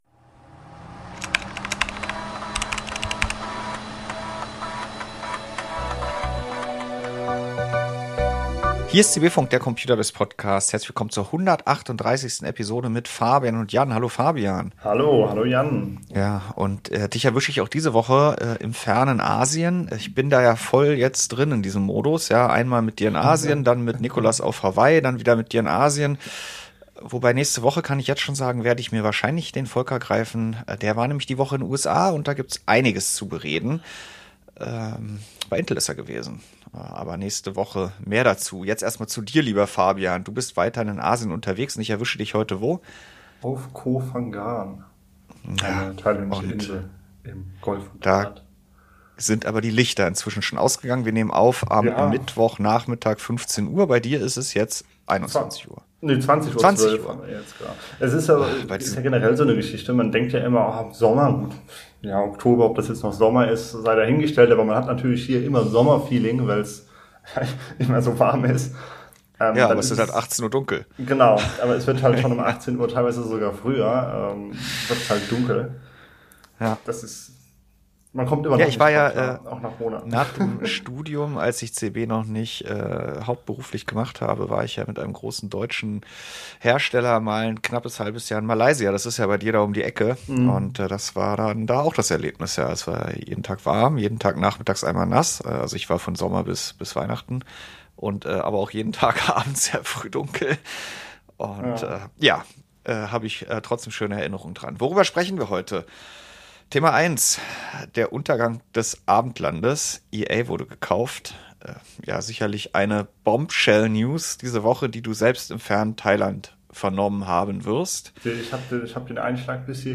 Die Redaktion von ComputerBase diskutiert im CB-Funk ganz ohne Gebrüll aktuelle Themen und gewährt Einblicke hinter die Kulissen: Warum hat die Redaktion wie berichtet oder getestet, was steht an, oder wo klemmt es?